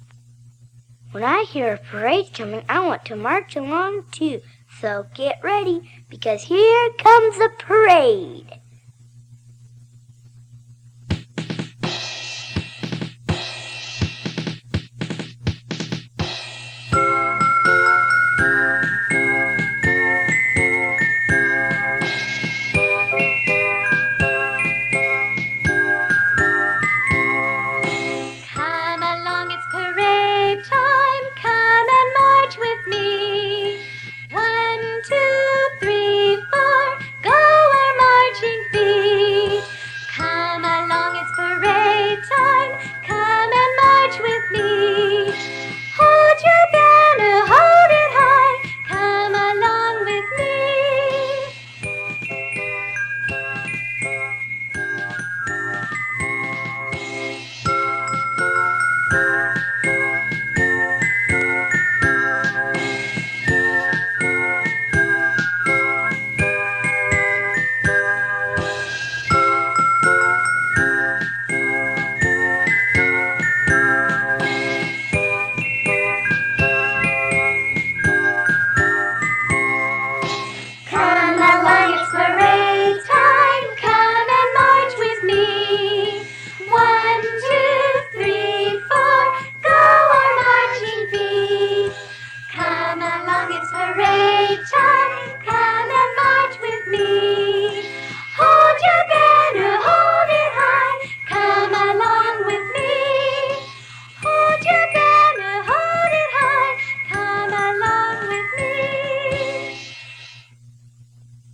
These are children songs for fun and learning.